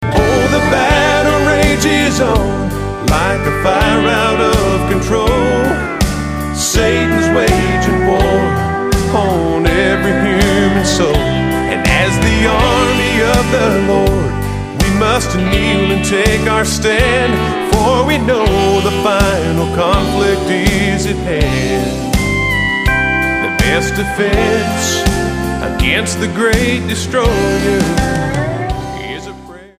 STYLE: Country
crisp production featuring steel guitar, dobros and fiddles